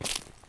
grass.wav